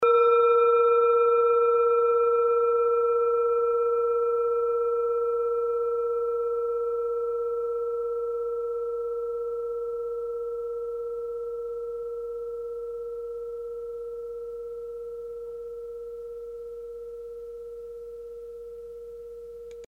Kleine Klangschale Nr.22 Bengalen
Sie ist neu und wurde gezielt nach altem 7-Metalle-Rezept in Handarbeit gezogen und gehämmert.
Hörprobe der Klangschale
(Ermittelt mit dem Minifilzklöppel)
In unserer Tonleiter liegt dieser Ton nahe beim "H".
kleine-klangschale-22.mp3